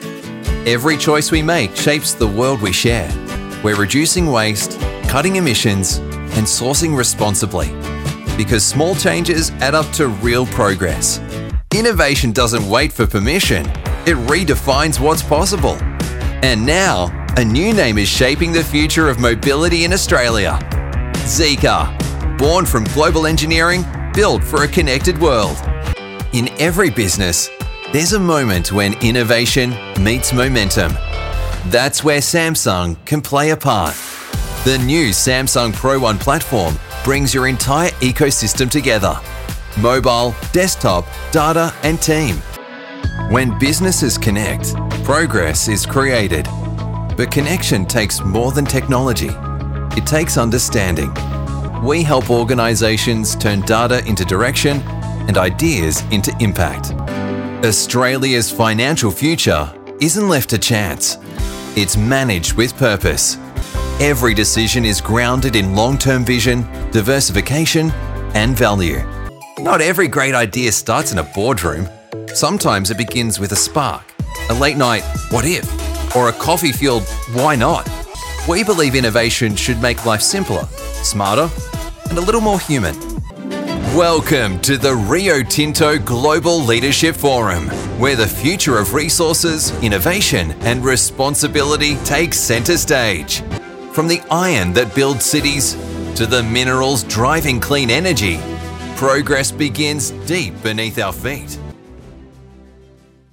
Adult (30-50)